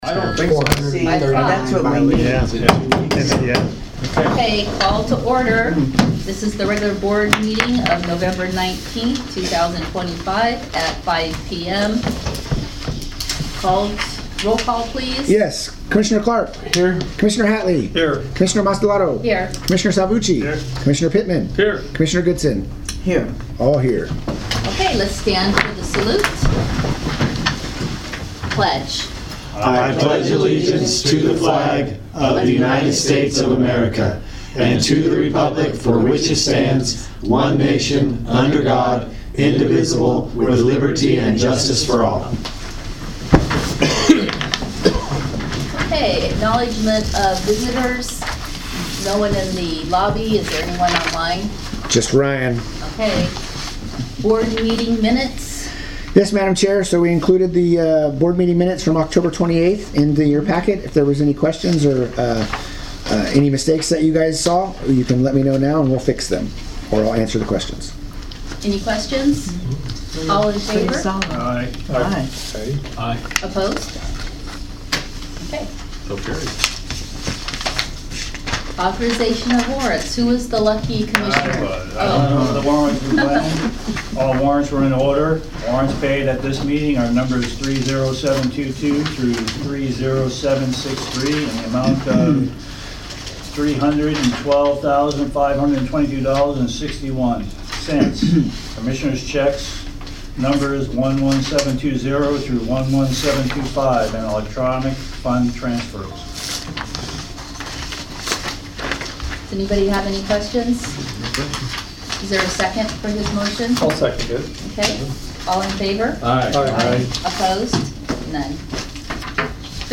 The Sewerage Commission - Oroville Region's Board of Commissioners meets on the fourth Tuesday of each month at 5:00 pm in the board room at its…
Board Meeting